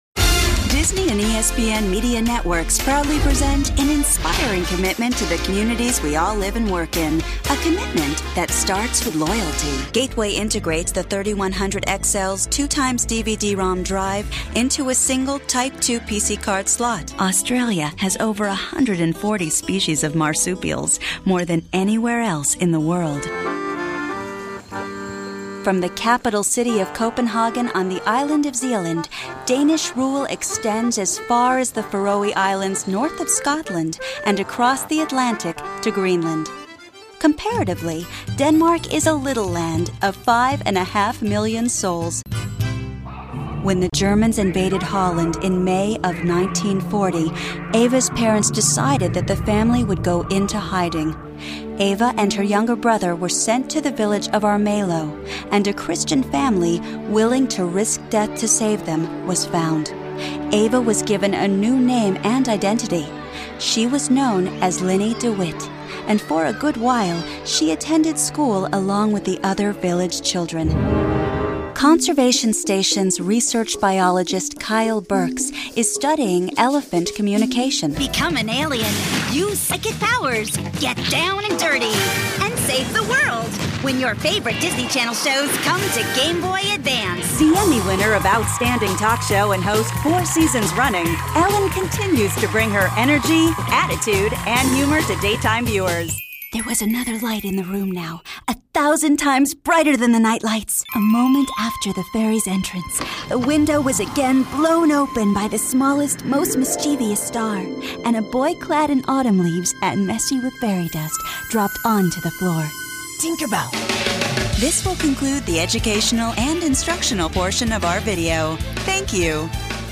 Versatile and seasoned voice.
informative, narrative, promo, storyteller